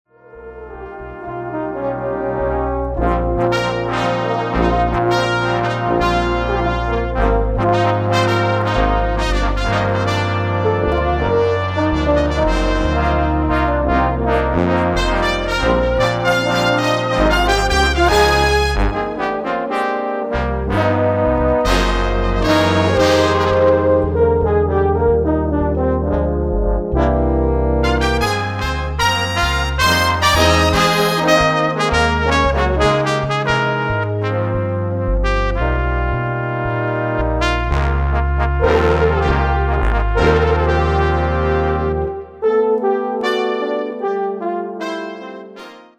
Established in 1997, Seaside Brass is a sextet of brass and percussion instrumentalists well versed in a variety of styles.